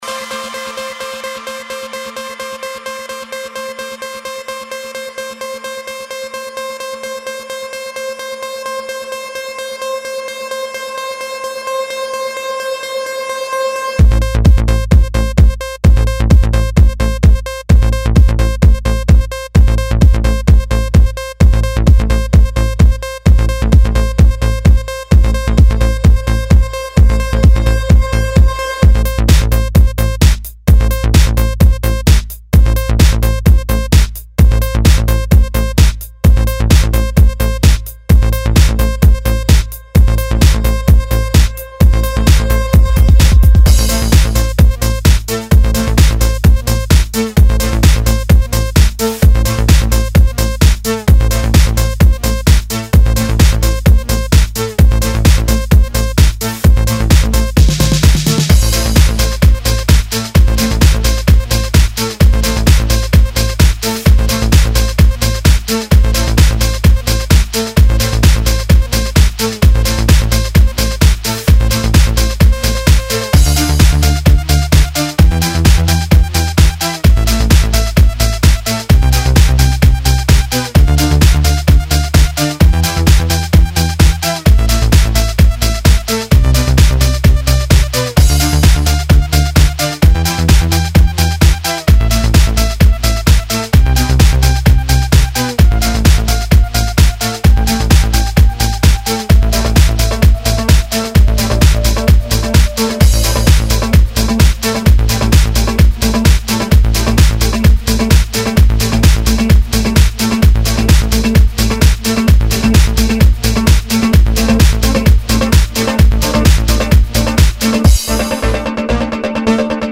tehno_haus_a_pod_etu_temu_neploho.mp3